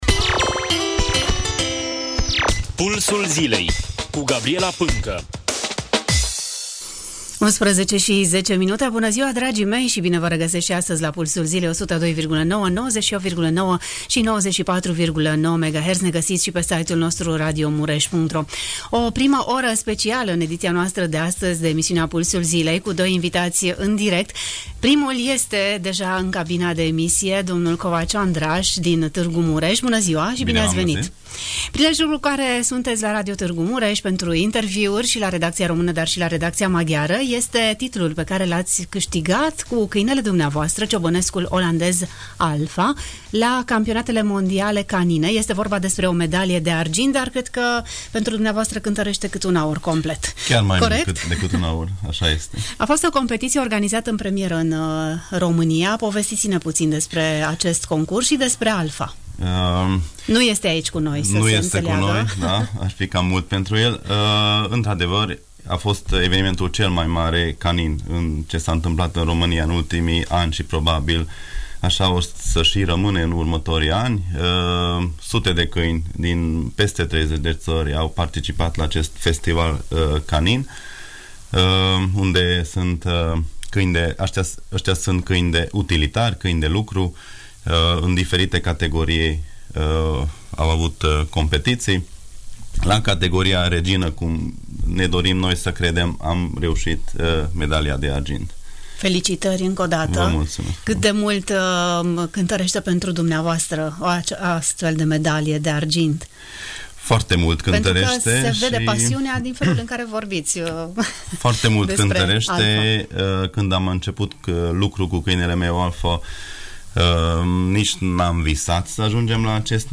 Interviul audio realizat în cadrul emisiunii Pulsul Zilei Radio Tg.Mureș: